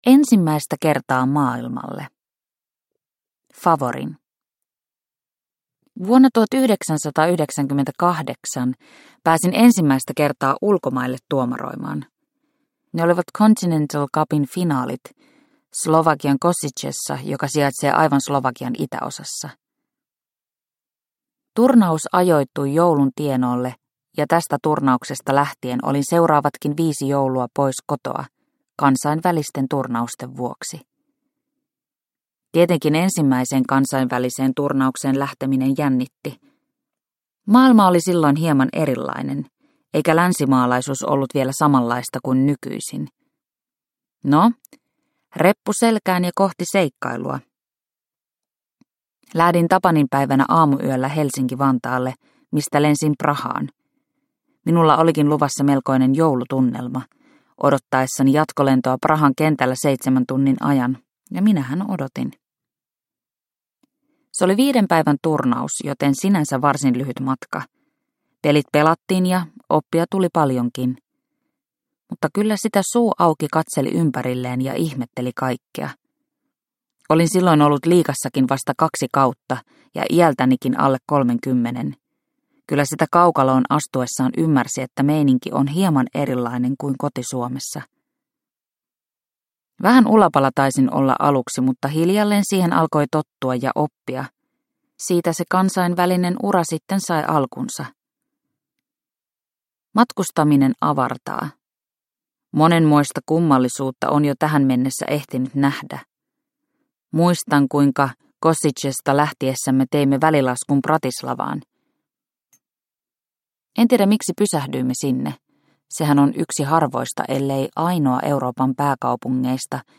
Tuomari – Ljudbok – Laddas ner